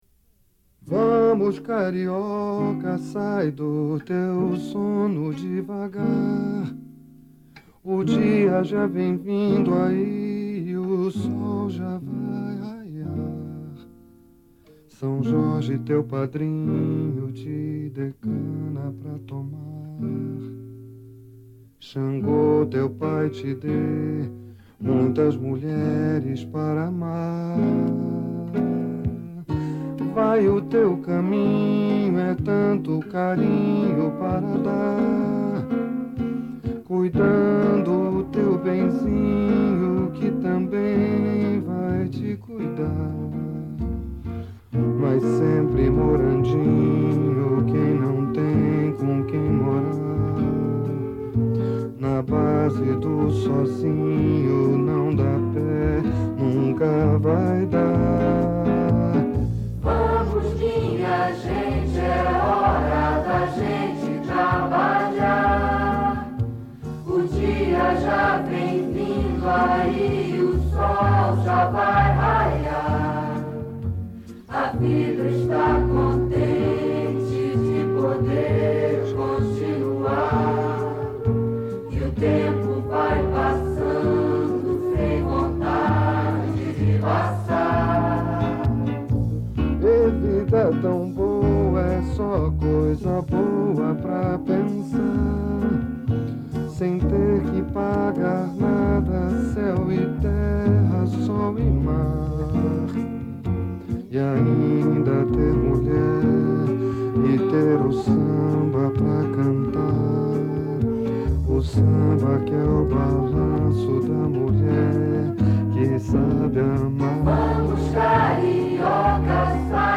Type: Gravação musical Subject